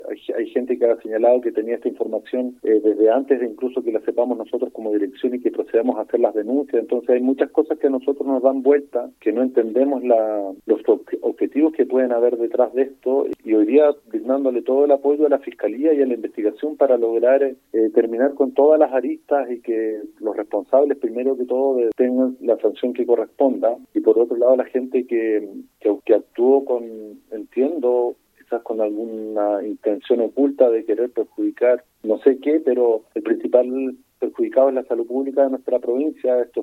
En entrevista con Radio Bío Bío en la ciudad, la autoridad sanitaria fue consultada por el ánimo que hay entre los trabajadores tras lo expuesto, replicando que hay “una consternación de toda la comunidad hospitalaria, esto nos ha pegado muy fuerte“.